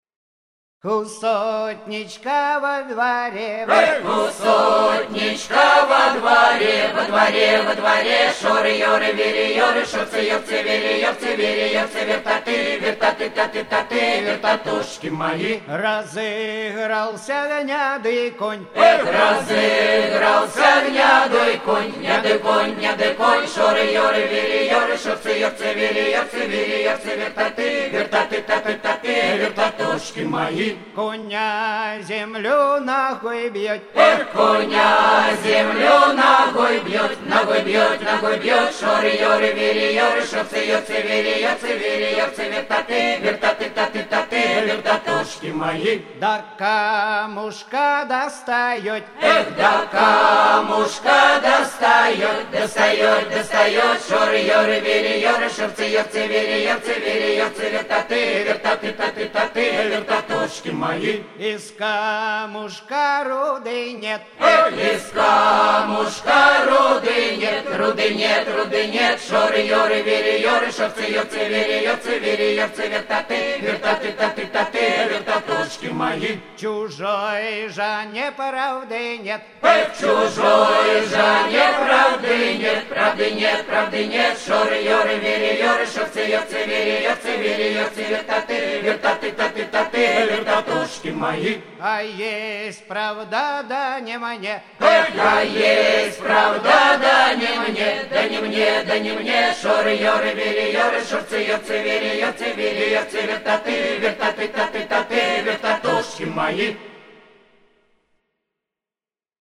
• Жанр: Детские песни
Ансамбль казачьей песни